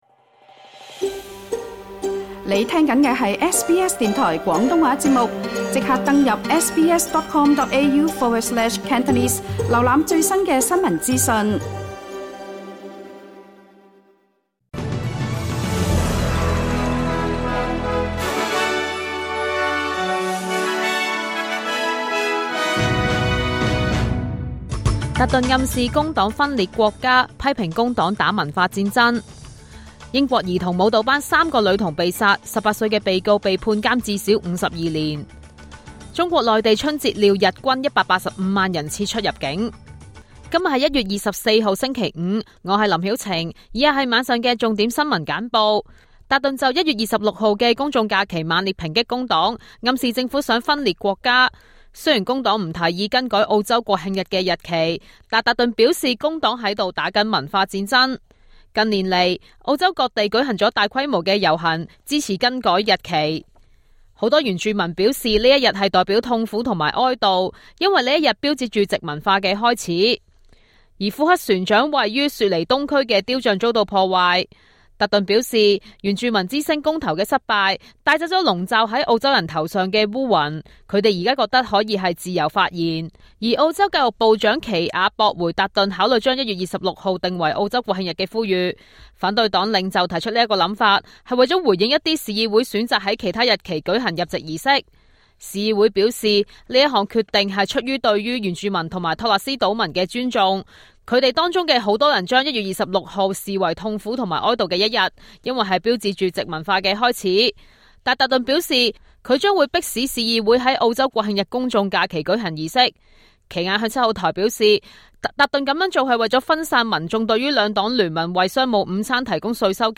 請收聽本台為大家準備的每日重點新聞簡報。